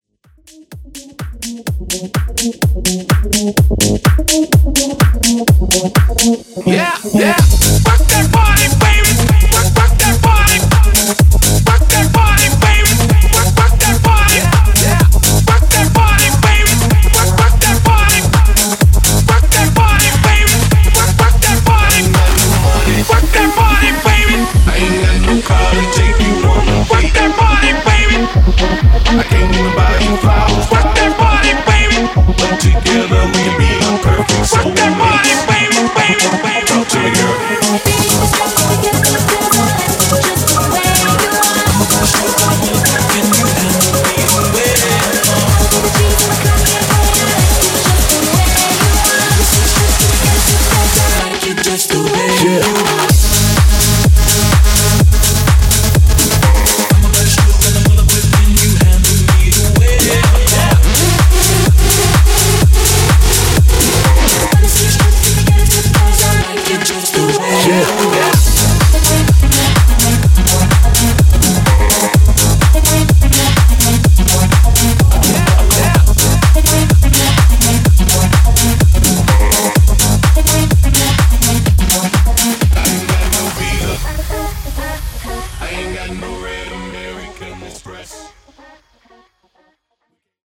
Club Hype Edit)Date Added